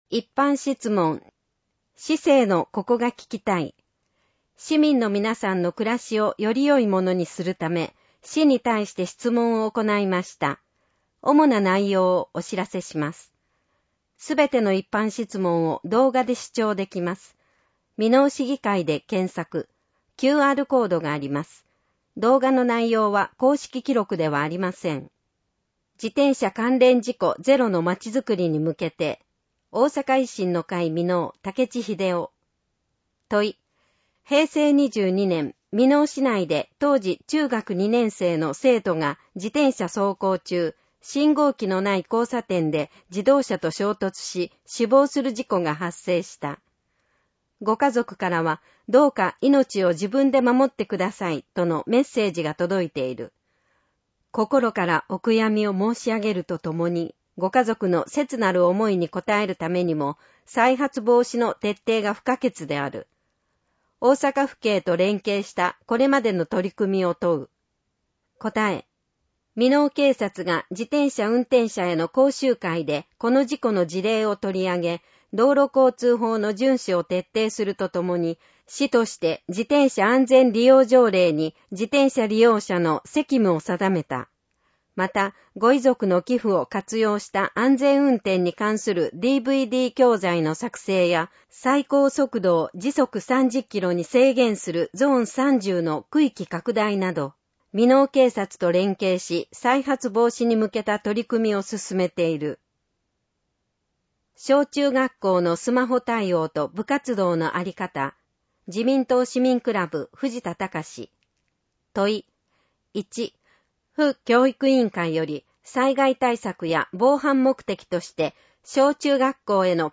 みのお市議会だより「ささゆり」の内容を声で読み上げたものを掲載しています。